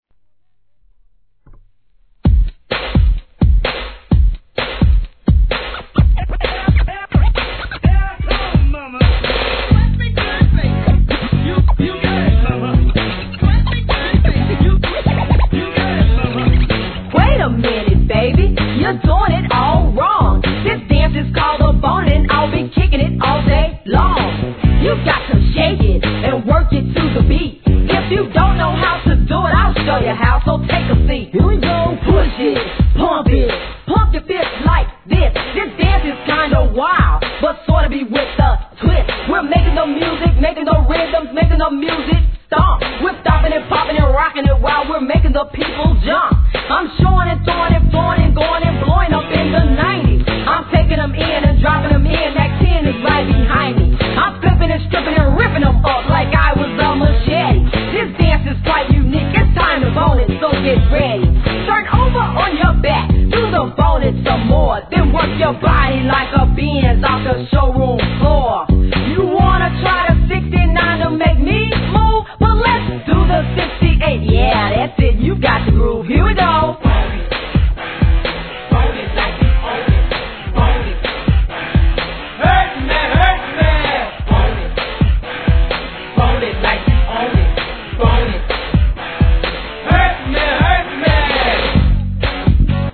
G-RAP/WEST COAST/SOUTH
聞き覚えのあるギターフレーズも飛び出すロック調のOLD SCHOOL色強い一曲!!